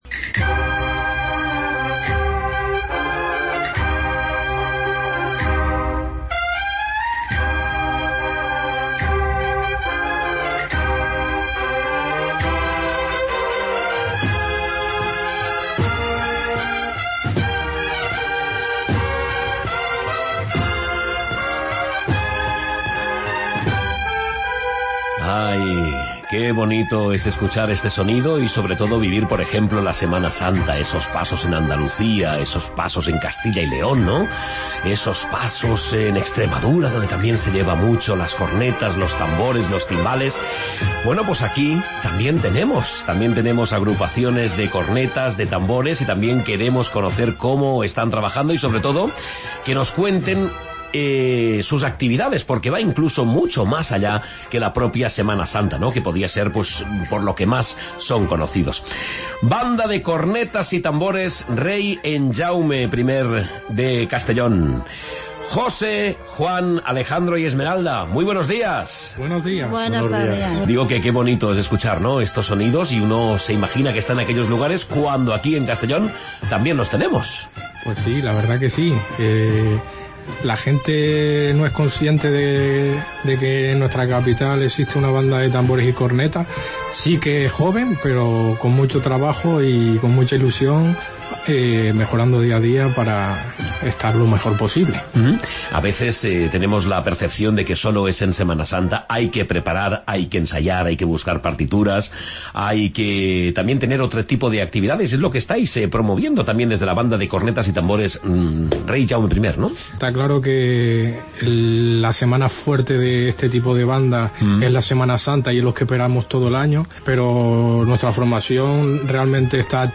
Entrevista con la Banda de Cornetas y Tambores Rei Jaume I